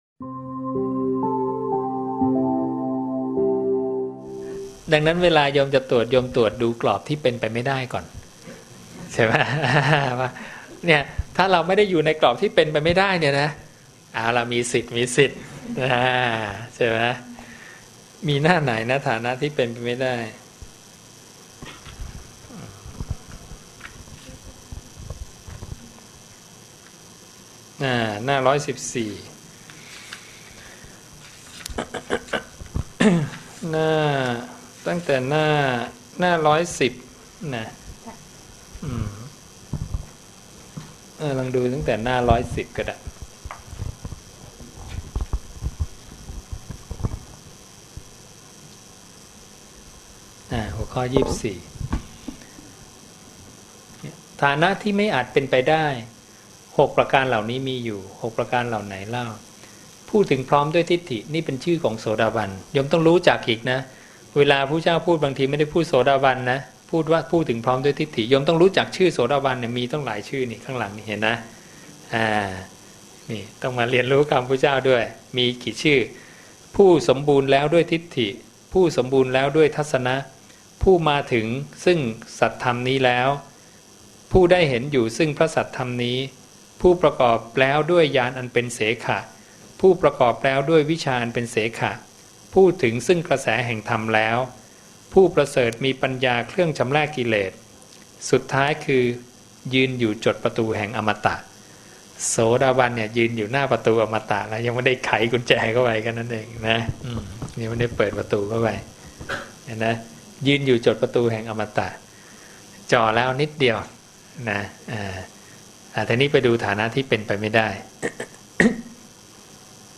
บรรยายธรรมโดย